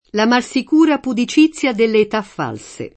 la mal Sik2ra pudi©&ZZLa delle et# ff#lSe] (Carducci) — poet. malsecuro [malSek2ro]: Soli all’affetto e malsecuri in terra [